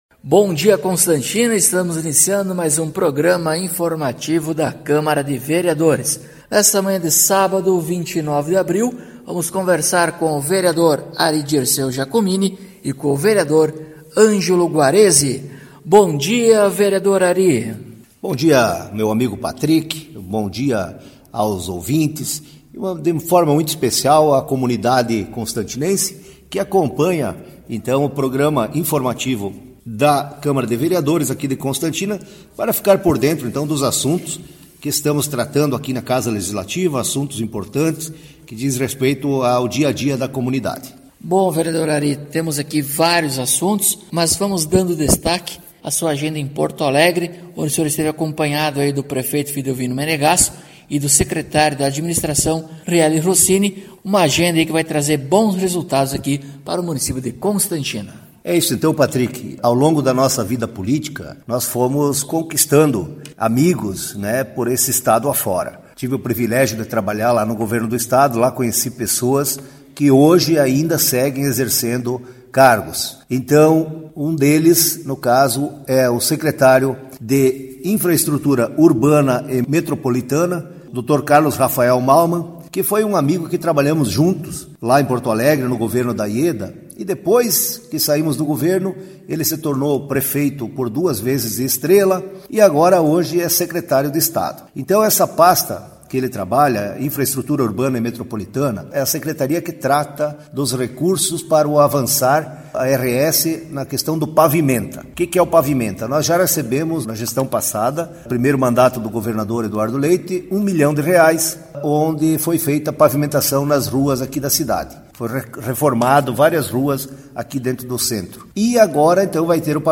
Acompanhe o programa informativo da câmara de vereadores de Constantina com o Vereador Ari Dirceu Giacomini e o Vereador Ângelo Guarezi.